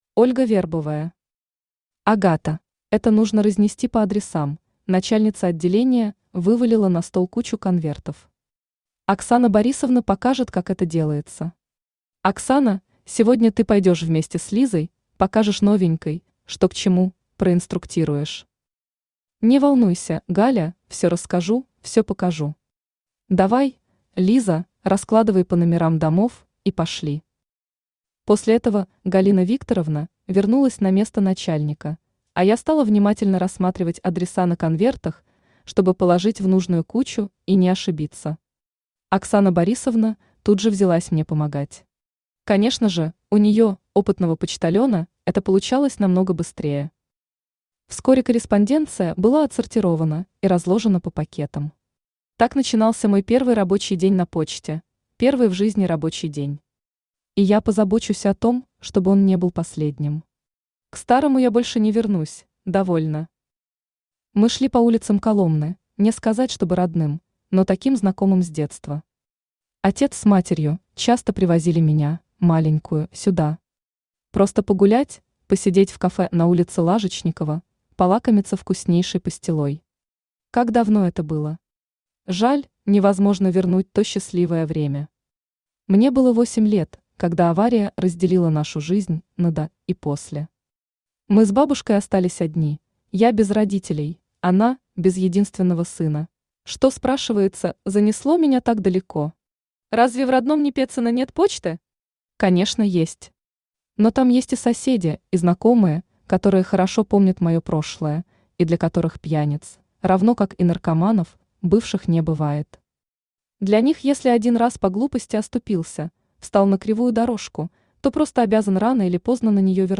Aудиокнига Агата Автор Ольга Леонидовна Вербовая Читает аудиокнигу Авточтец ЛитРес.